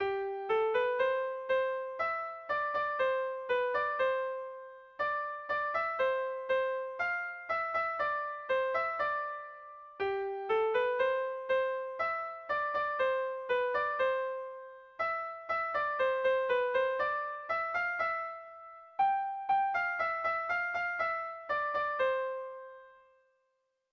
Bertso melodies - View details   To know more about this section
Irrizkoa
Bost puntuko berdina, 11 silabaz
ABADE